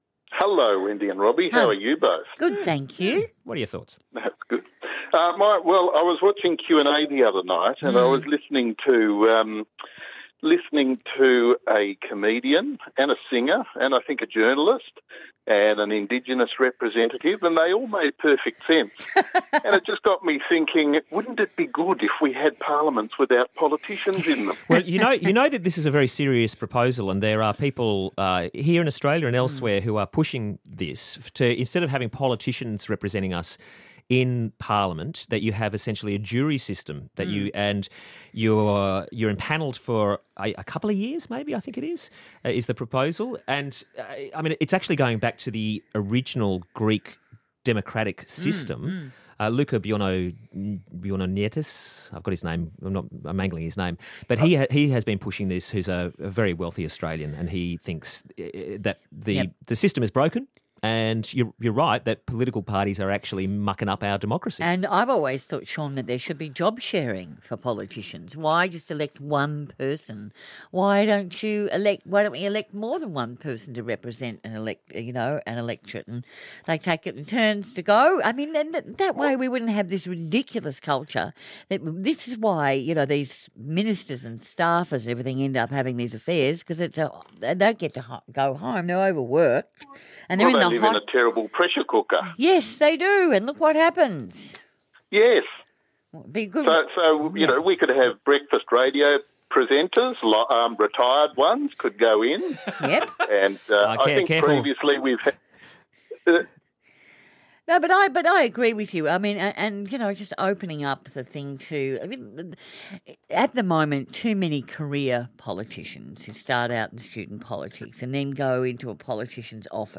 Wendy Harmer and Robbie Buck discuss citizens' assemblies and democratic lotteries.